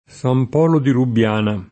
Sam p0lo di rubbL#na] (Tosc.), San Polo dei Cavalieri [Sam p0lo dei kavalL$ri] (Lazio), ecc.